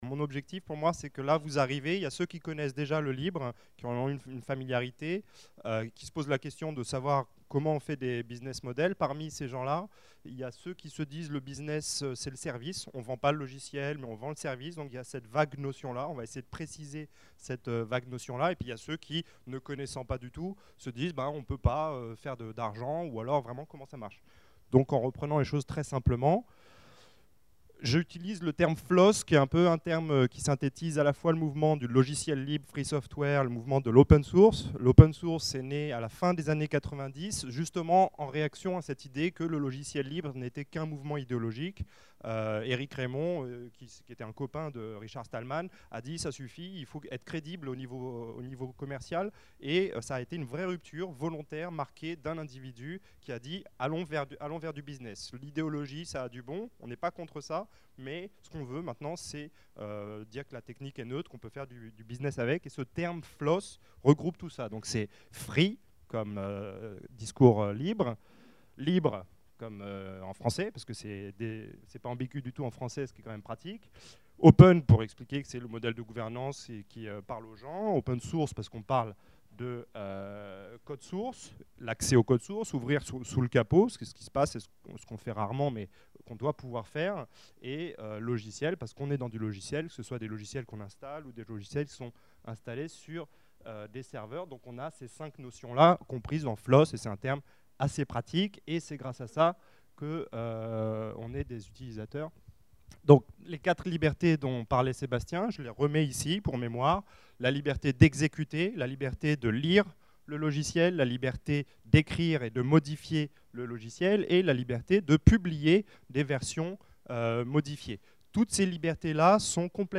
Deuxième rendez-vous autour du logiciel le 6 mars de 19h à 22h à Mutinerie (29, rue de Meaux, 75019).